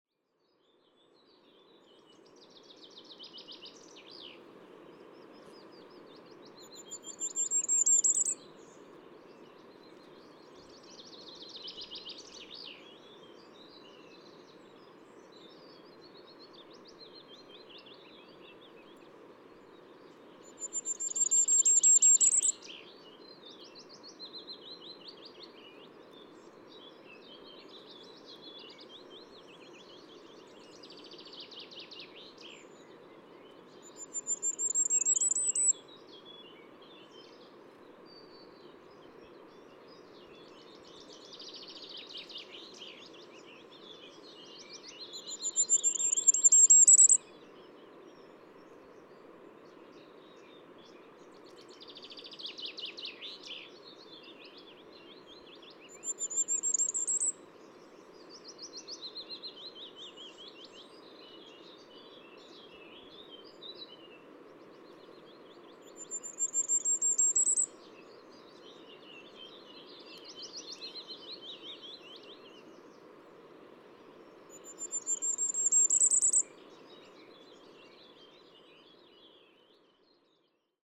Krkonoše National Park
Common Redstart Phoenicurus p. phoenicurus, adult male, song